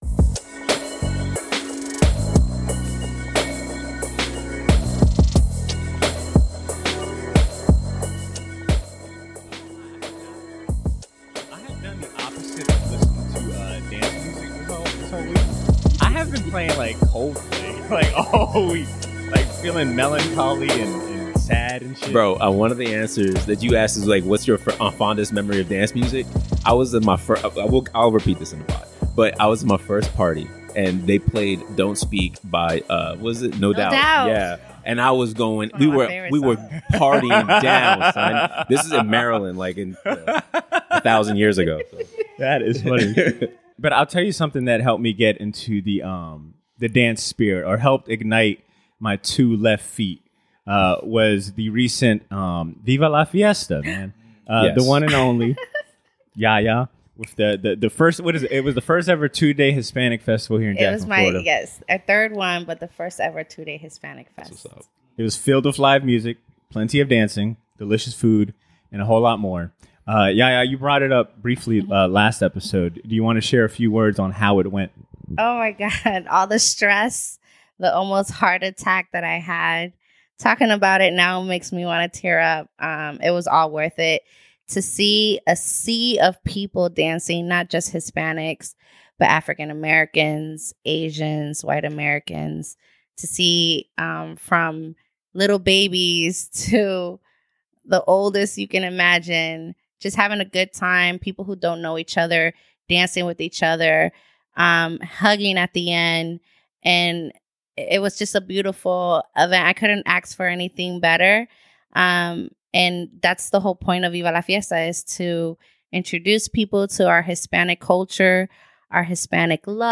The Nexxt Spin crew is riding high after being named a Best 10 Record Collecting Podcast. The group talk about the music that make them move their feet, and their favorite memories from the dance floor, with special guest co-host